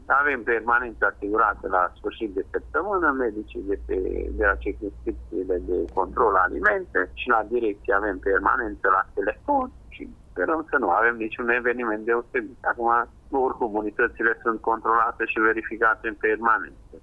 Totodată, în perioada 27 aprilie – 1 mai, inspectorii nu vor avea zile libere spune directorul DSVSA Mureș, Kincses Sandor: